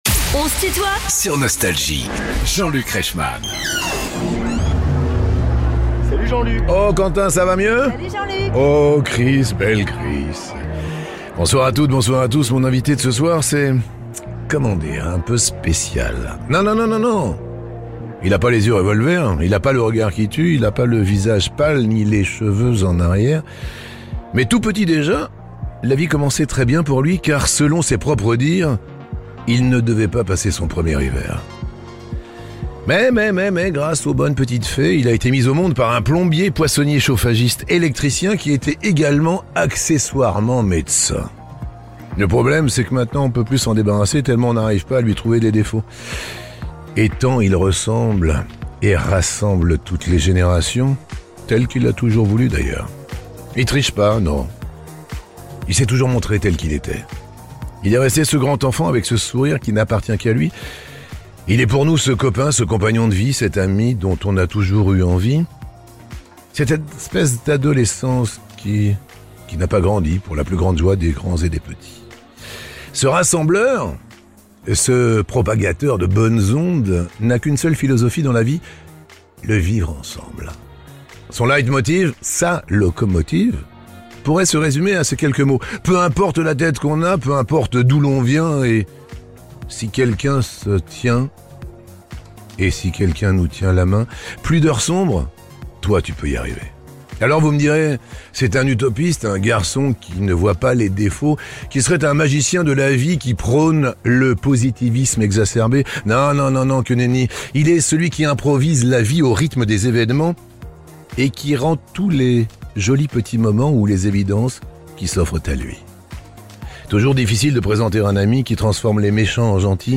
Booder, l’humoriste au grand cœur est l'invité de "On se tutoie ?..." avec Jean-Luc Reichmann (partie 1) ~ Les interviews Podcast